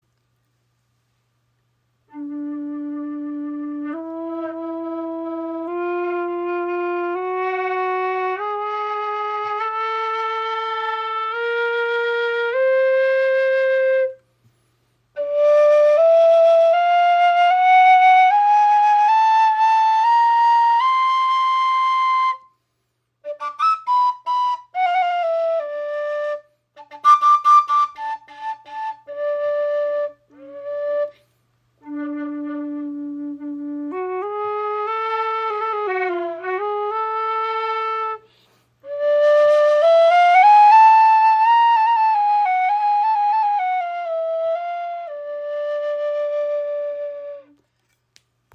Kaval / Neyval | 7 Grifflöcher | D
Die Halbtonschritte in ihrer Stimmung geben ihr einen orientalischen oder Balkan Charakter.
Stimmung: D - E F F# Ab A Bb C
In den sanften Schwingungen der Kaval liegt ein zarter Klang, wie seidiger Nebel, der sich um die Sinne webt.